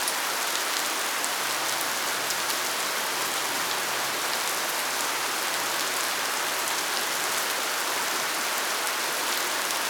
rain_heavy_loop_02.wav